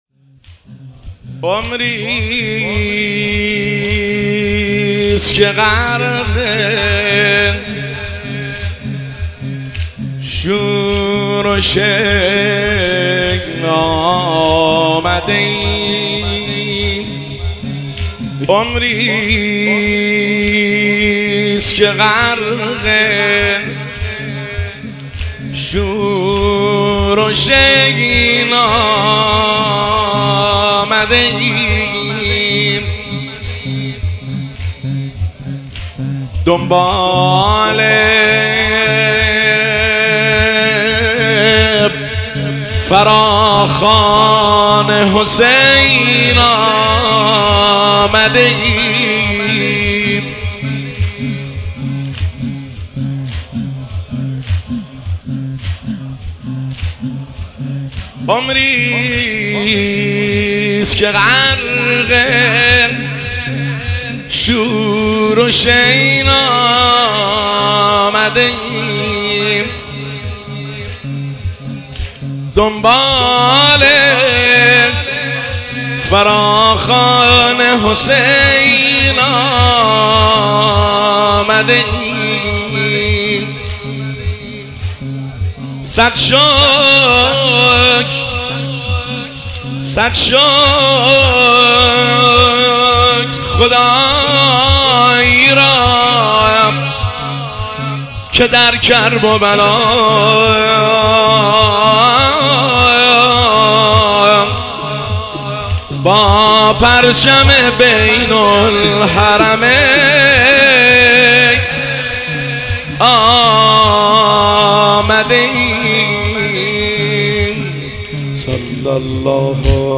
زمزمه شب پنجم اربعین حسینی 1404